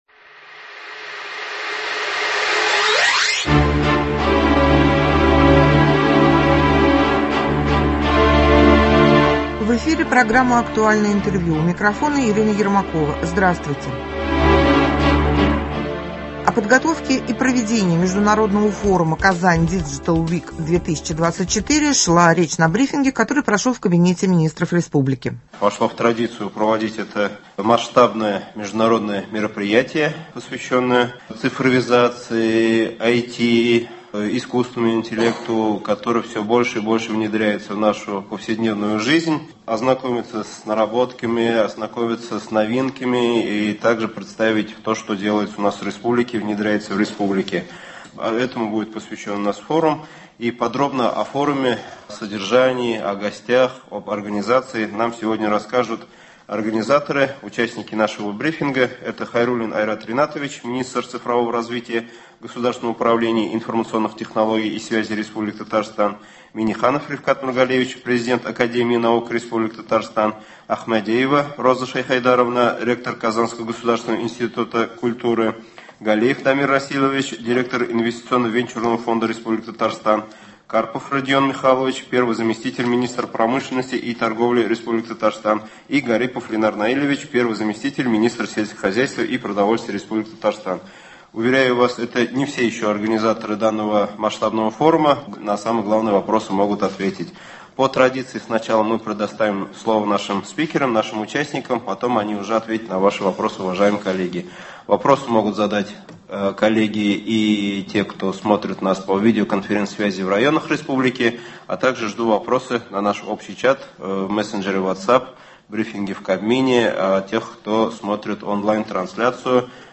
Актуальное интервью (04.09.24)